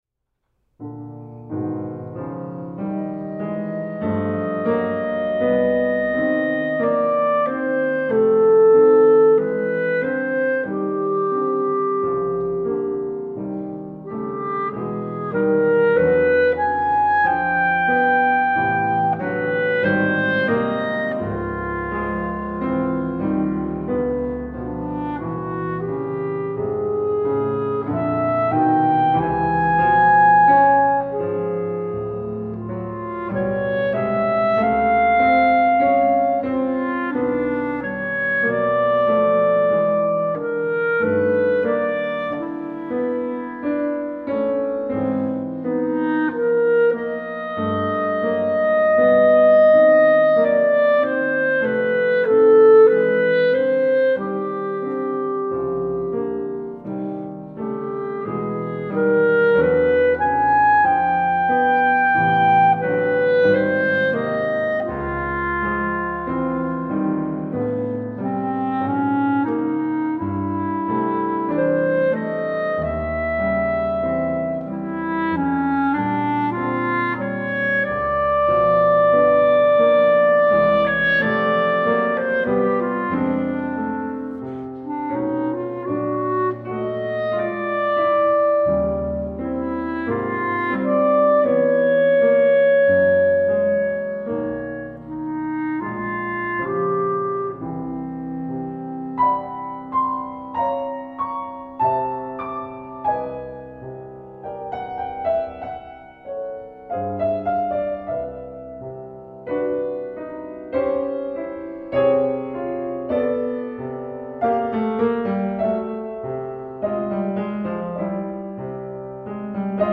3. Slow, languorous